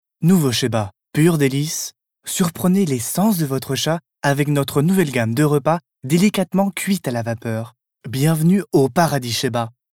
Voix OFF reportage TV